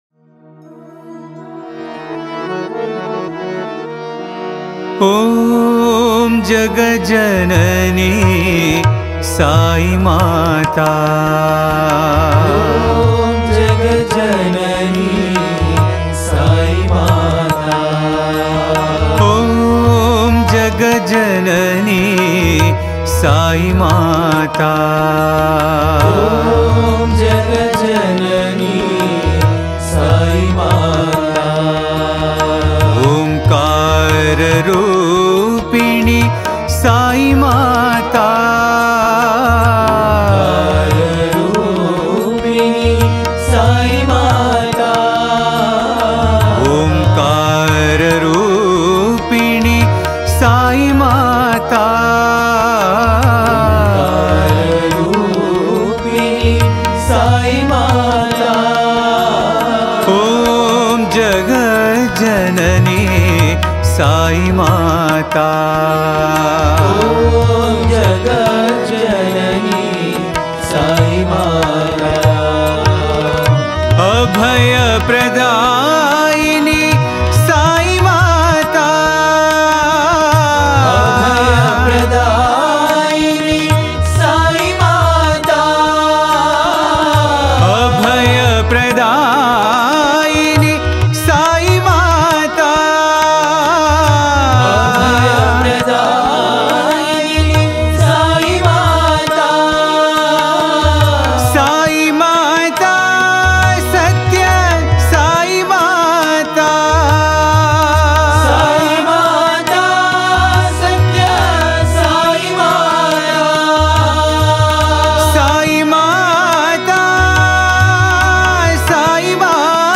Author adminPosted on Categories Devi Bhajans